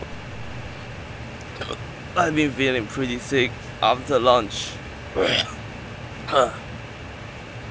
illness3_AirConditioner_2.wav